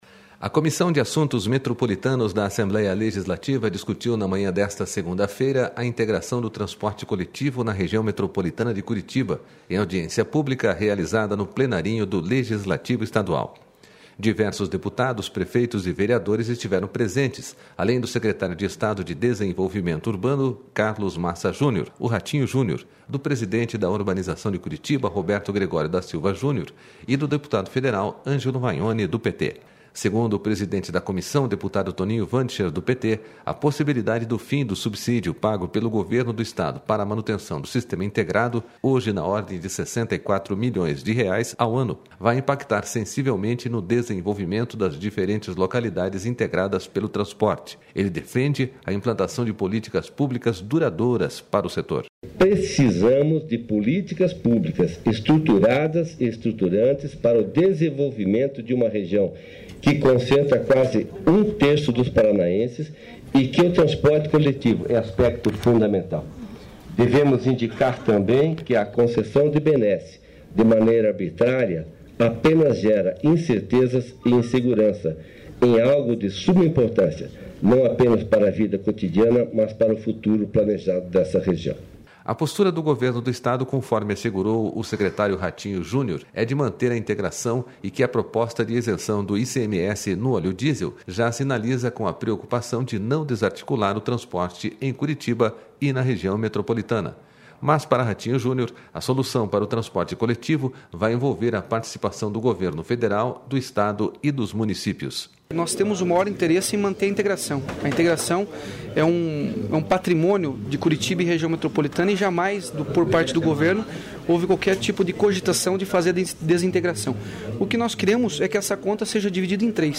A Comissão de Assuntos Metropolitanos da Assembleia Legislativa discutiu na manhã desta segunda-feira a integração do transporte coletivo na Região Metropolitana de Curitiba, em audiência pública realizada no Plenarinho do Legislativo estadual.//Diversos deputados, prefeitos e vereadores estiveram p...